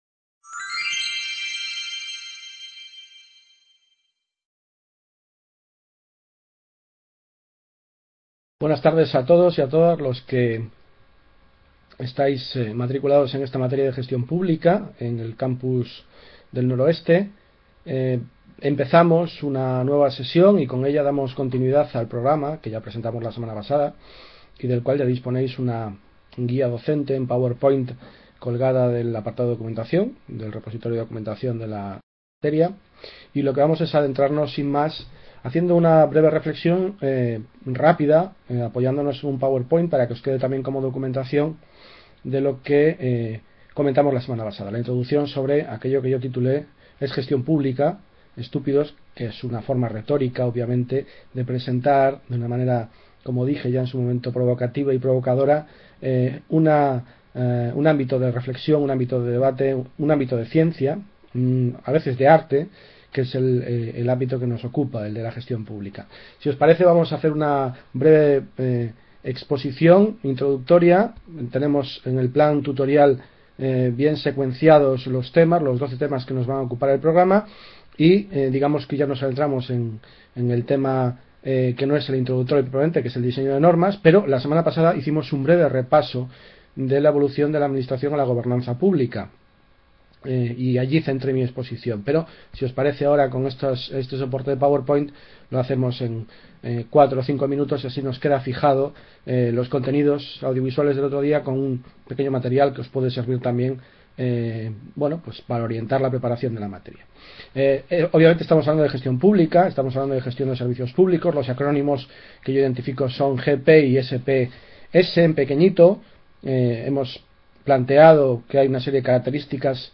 Webconferencia (8-III-2013): ¿SE CONSTRUYE UNA… | Repositorio Digital